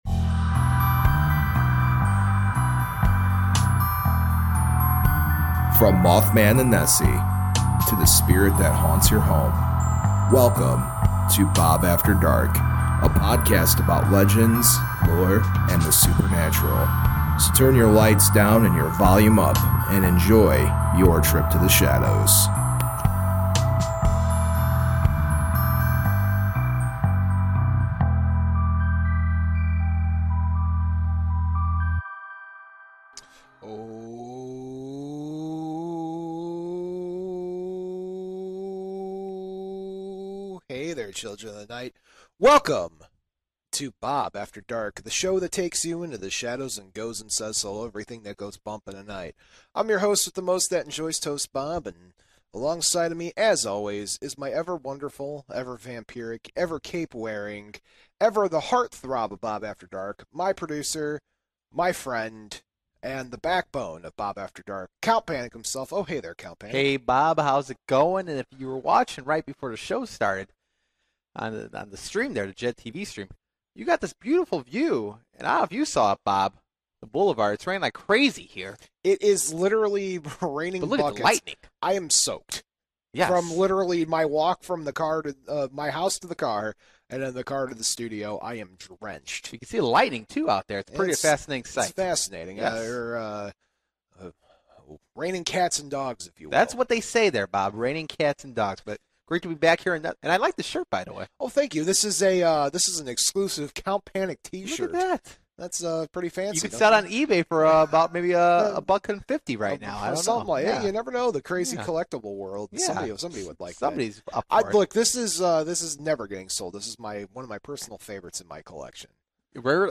This week on Bob After Dark, we interview someone a little different than what we are used to on the show.